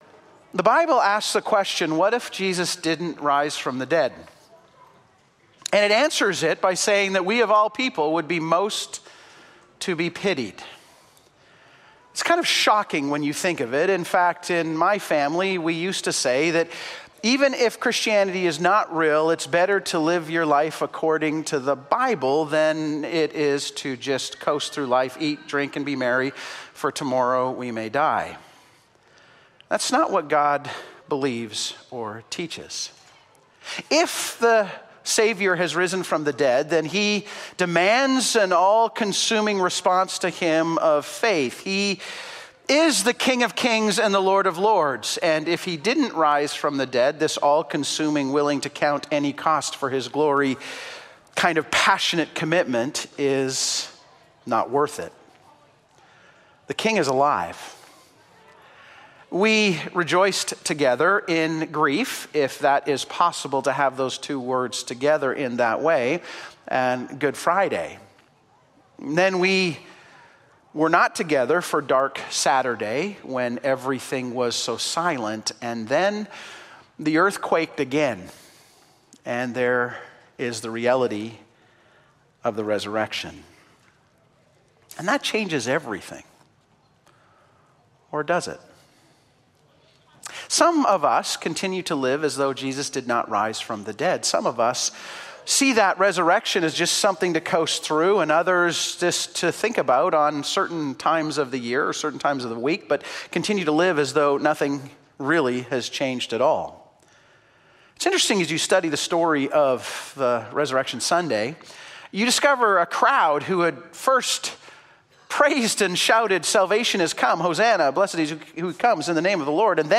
Sermons see more